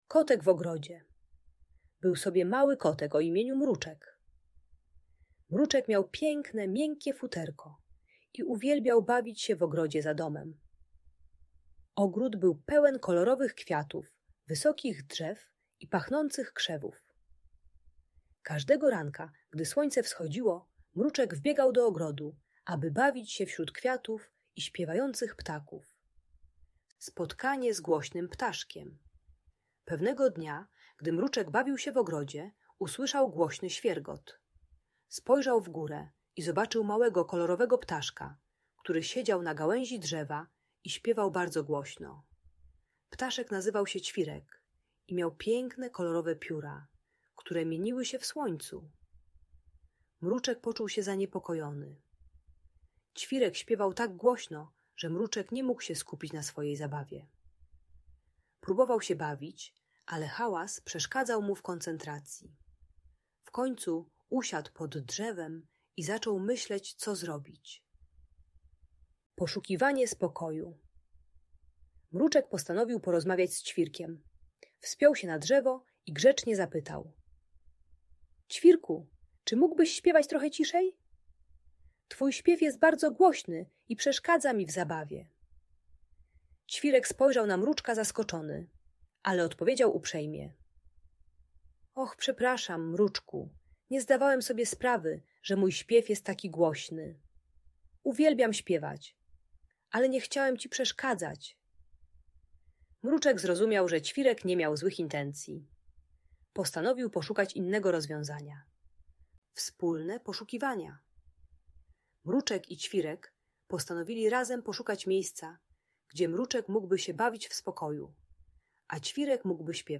Kotek w Ogrodzie - Przedszkole | Audiobajka